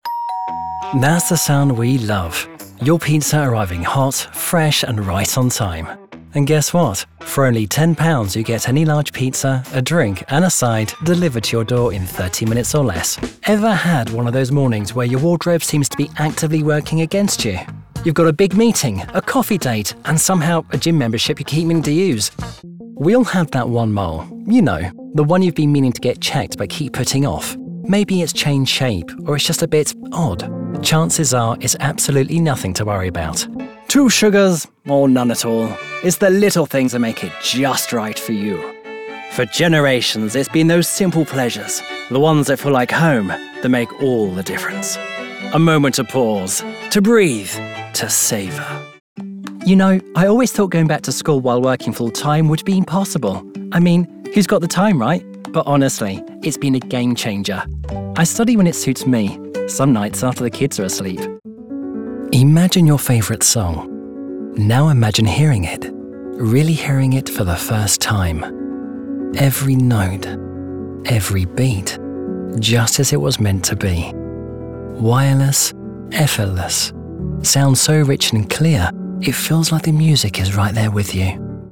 Comercial, Natural, Llamativo, Amable, Joven, Empresarial
Comercial